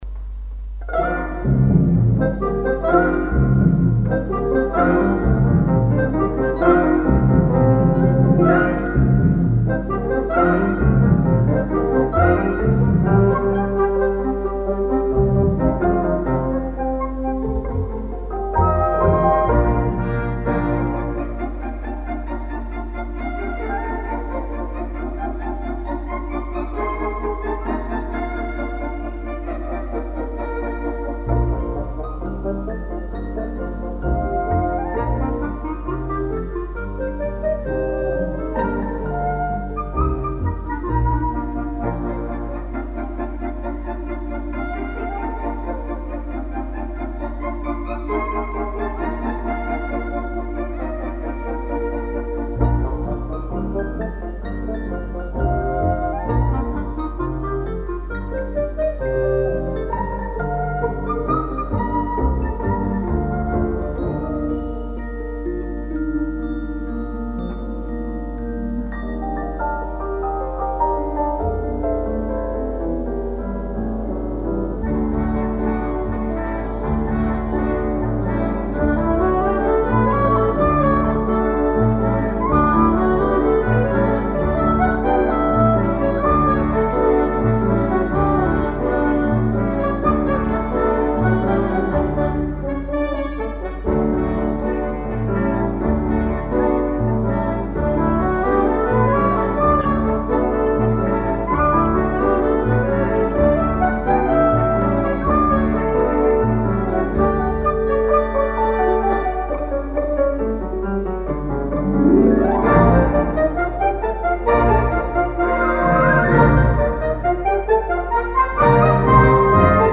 Here is a taster for you: Frolic No. 1 (Deoíndí) for orchestra (1951)